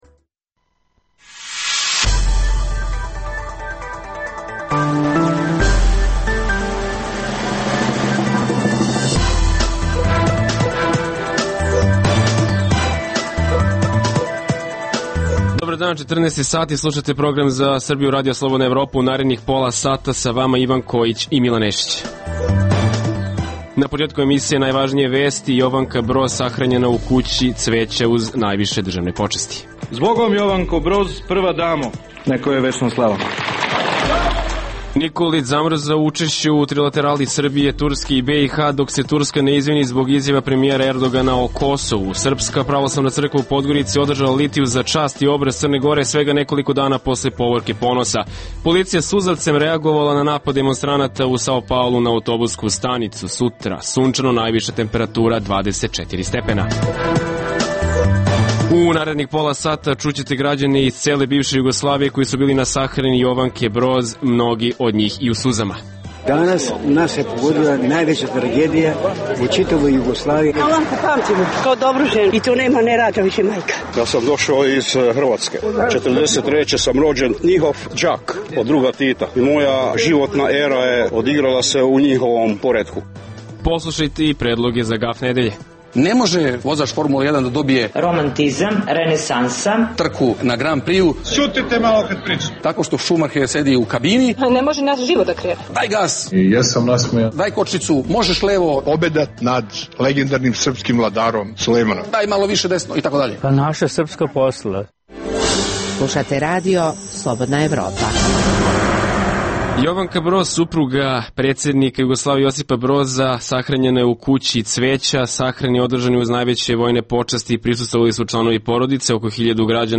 - Čućete građane iz cele bivše Jugoslavije koji su bili na sahrani Jovanke Broz - mnogi od njih i u suzama. - Nikolić zamrzao učešće u trilaterali Srbije, Turske i BiH, dok se Turska ne izvini zbog izjava premijera Erdogana o Kosovu. - Srpska pravoslavna crkva u Podgorici održala litiju za čast i obraz Crne Gore svega nekoliko dana posle Povorke ponosa.